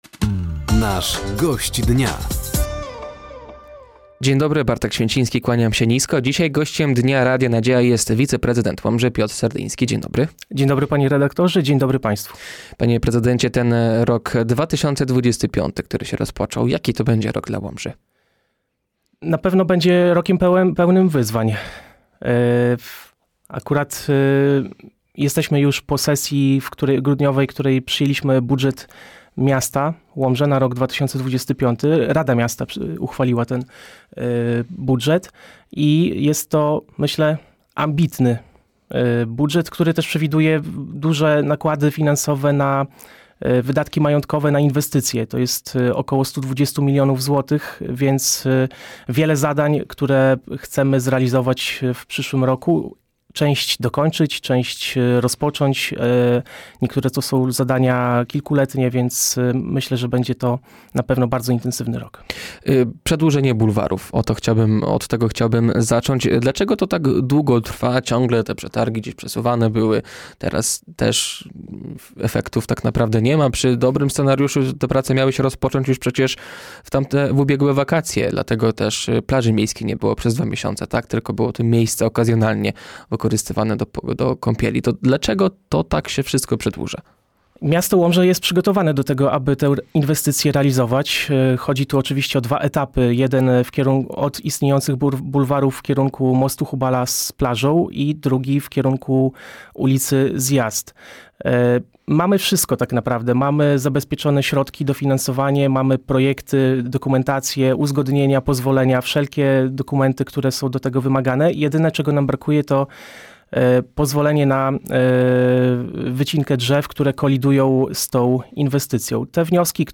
Gościem Dnia Radia Nadzieja był wiceprezydent Łomży Piotr Serdyński. Tematem rozmowy była między innymi budowa bulwarów, karta mieszkańca, podatki oraz sytuacja w łomżyńskiej PO.